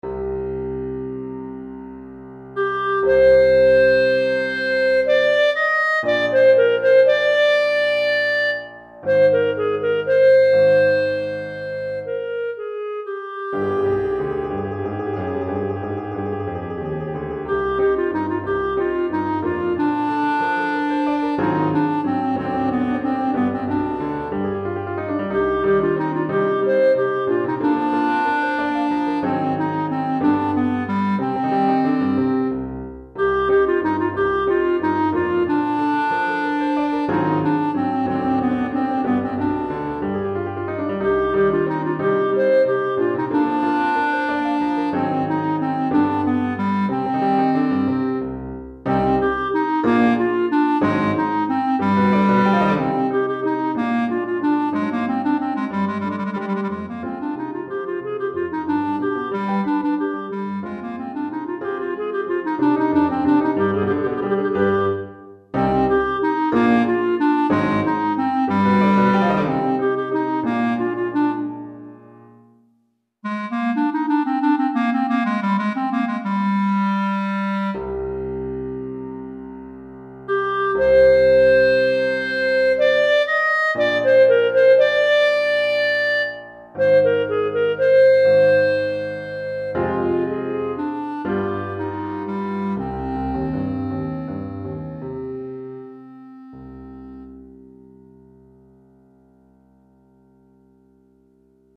Clarinette et Piano